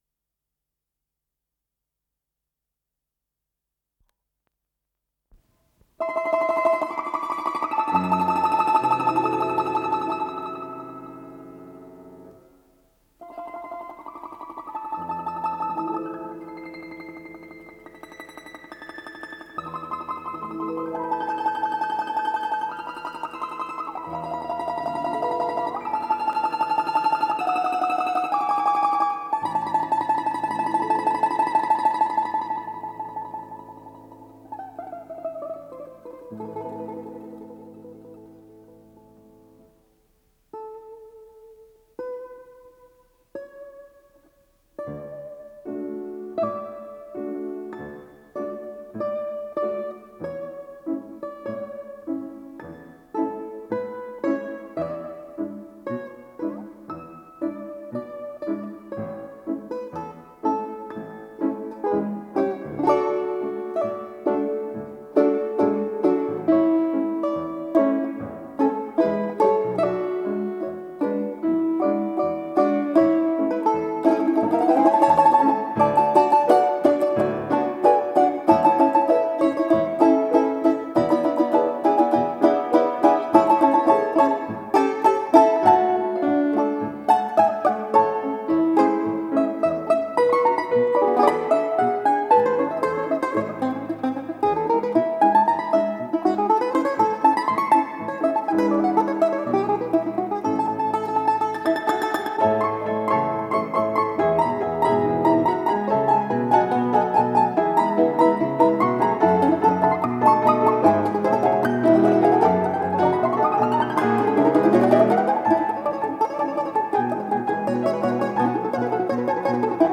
домра
фортепиано
ВариантДубль моно